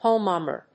意味・対訳 ホモマー